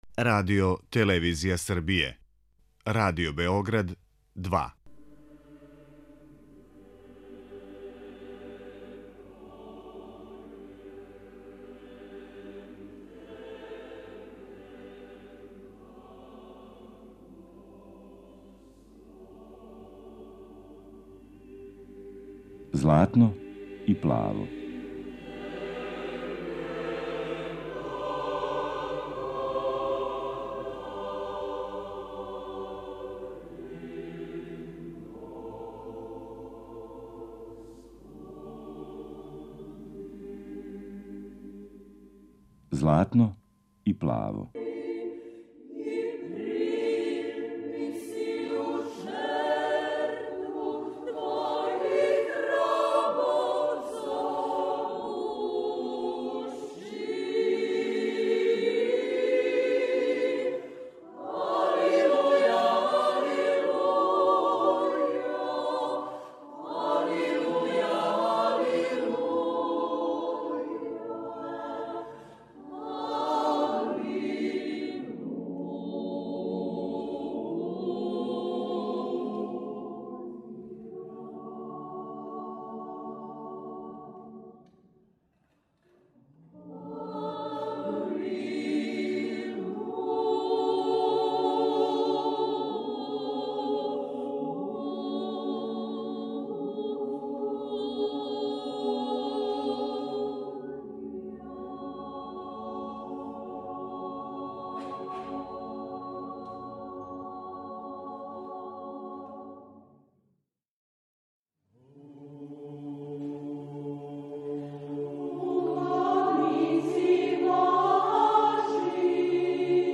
Емисија посвећена православној духовној музици.
Збирком аудио-снимака начињено је још једно звучно сведочанство о виталности традиционалног српског црквеног појања, нашег јединственог духовног и културног наслеђа.